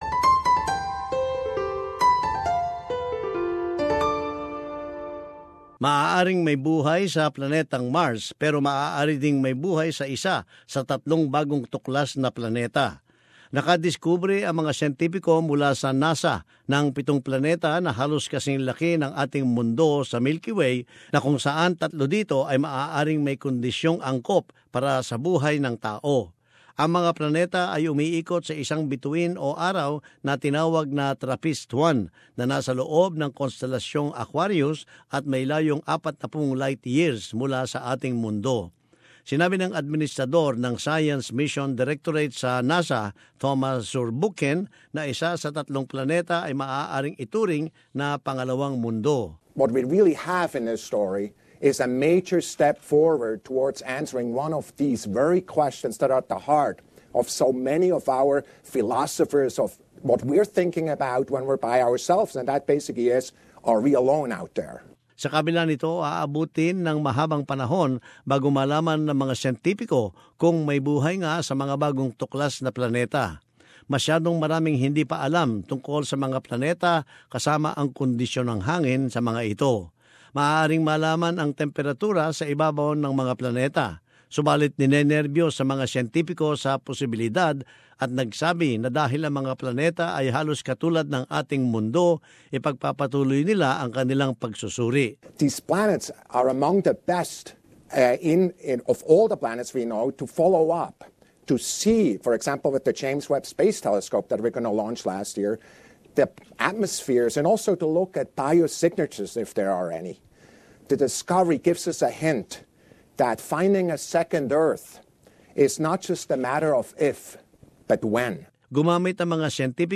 The United States space agency NASA* (NASS-uh) has discovered seven new earth-sized planets in a nearby solar system, and three have the potential to contain life.